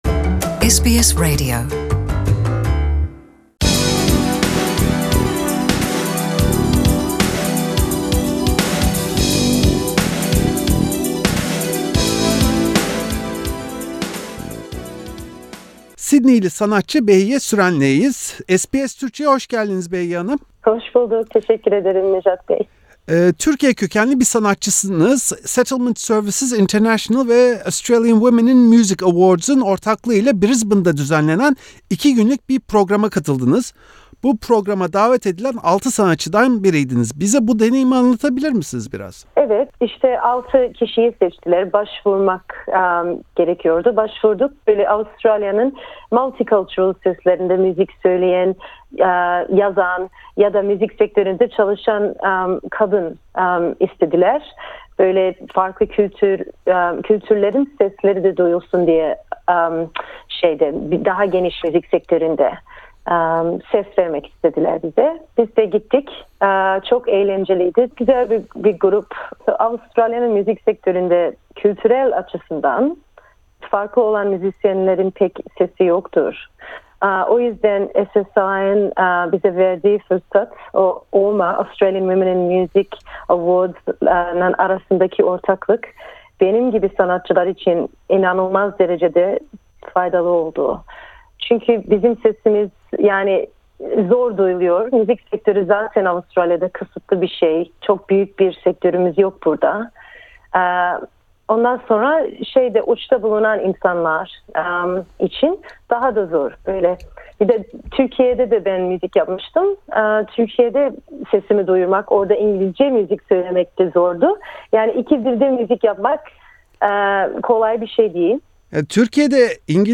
SBS Türkçeyle yaptığı röportajda, etkinliğin kendisi için ne kadar yararlı olduğunu anlattı. İki yıldır yapılan bu etkinlikte amaç, tüm dünyada olduğu gibi Avustralya muzik sektöründe kadın varlığının son derece sınırlı olmasına karşı bazı önlemler almak. Hedef, müzik sektöründeki kadınların birbiriyle veya erkeklerle rekabet etmemesi, işbirliği yapması ve bu şekilde birbirlerini desteklemeleri.